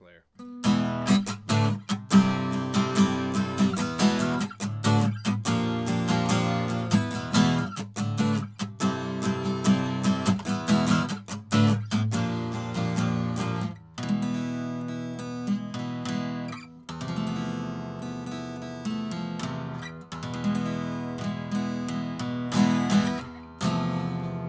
) Overall I say they show me a lot more air when the proximity effect is happening, and overall flatness and better bass response.
Cardioid Acoustic Guitar -
These recordings were done with a Focurite Voicemaster Pro using the SPDF out ( only the pre was activated - no effects ) .
Apex460-Stock-Card-Acoustic.wav